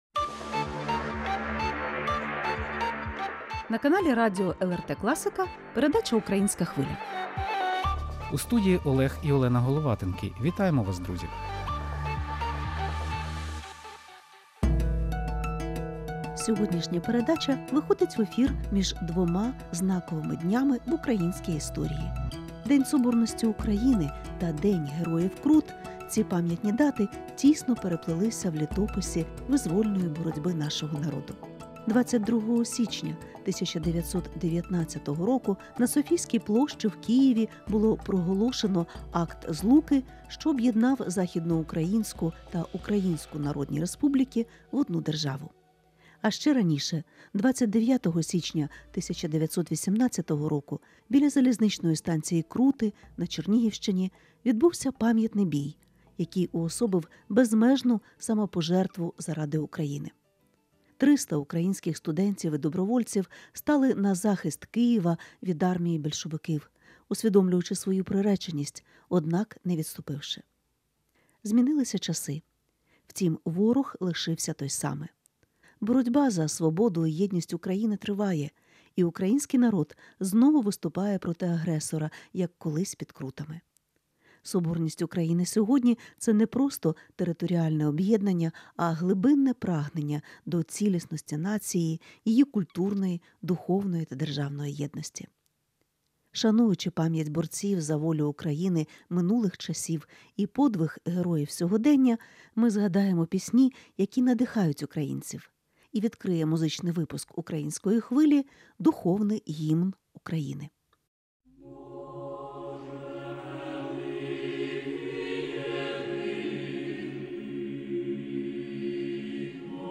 Шануючи пам’ять борців за волю України минулих часів і подвиг героїв сьогодення, у музичному випуску “Української Хвилі” згадаємо пісні, присвячені цим історичним подіям, пісні, що надихають українців.